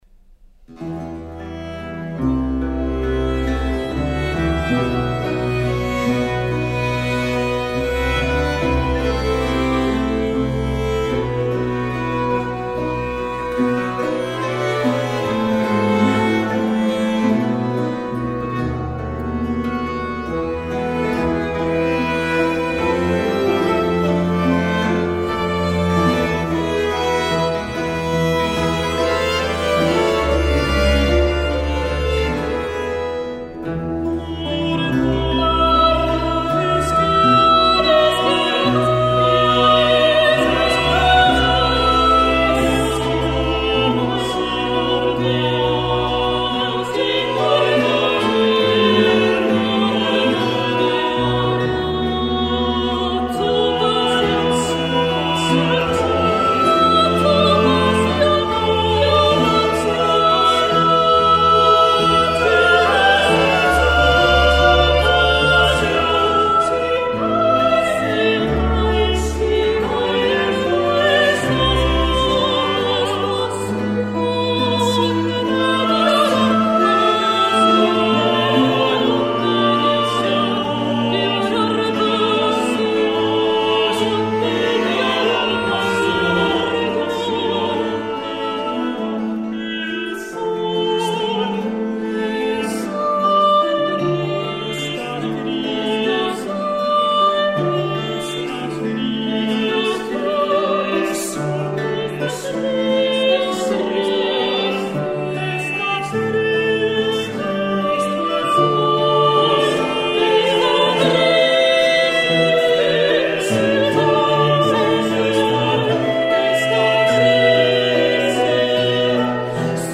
La tonalité  d’ensemble est baroque